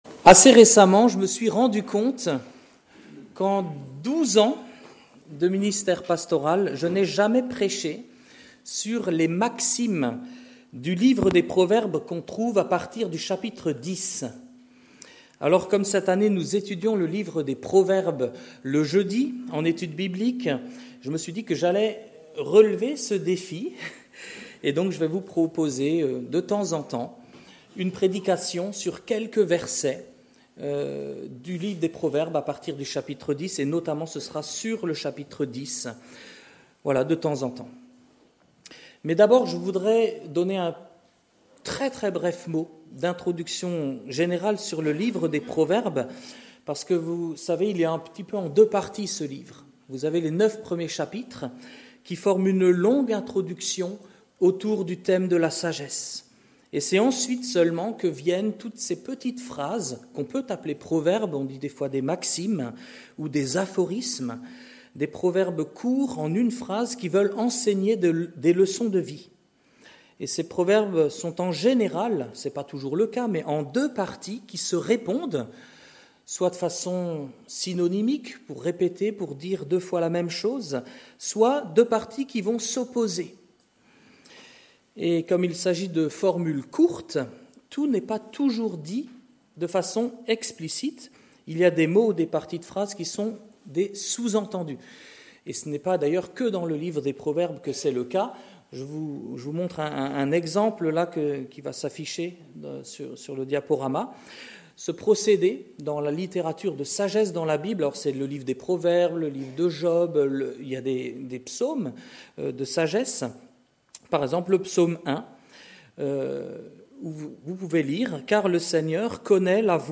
Culte du dimanche 12 janvier 2025 – Église de La Bonne Nouvelle